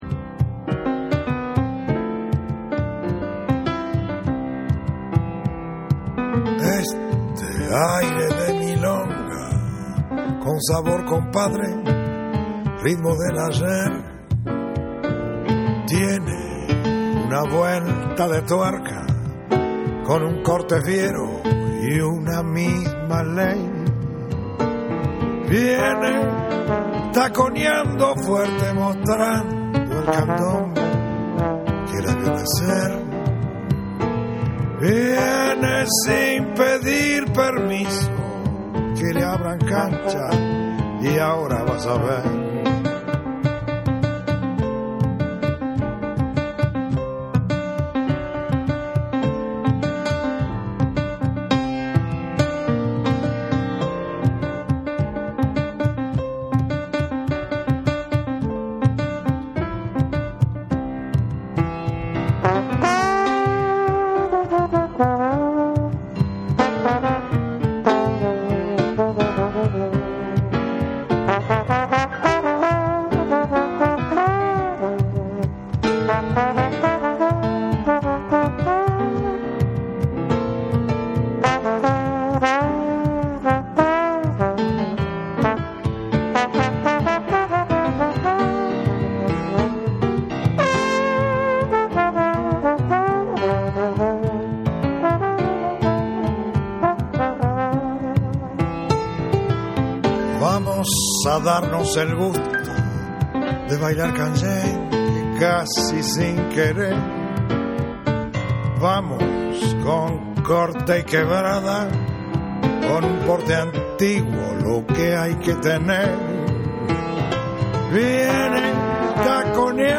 土着的なリズムに洗練されたメランゴリックな演奏が哀愁を誘う好作。
WORLD / CD